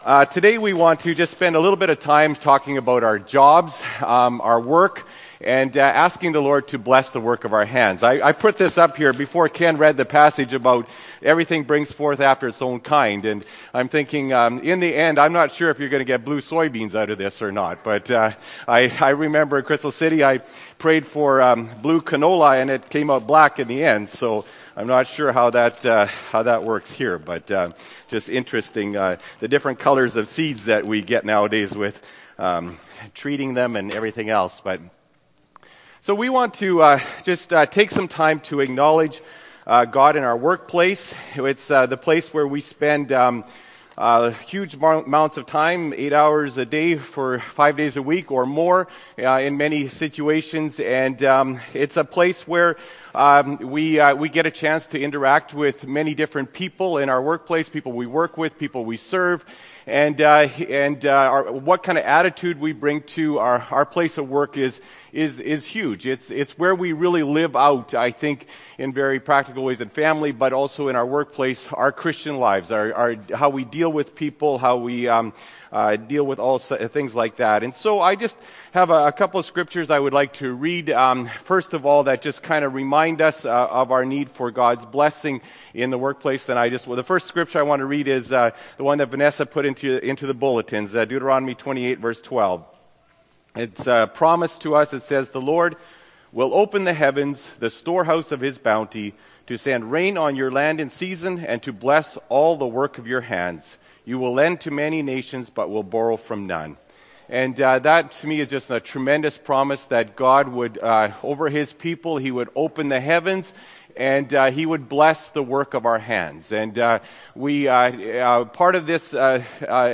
May 4, 2014 – Sermon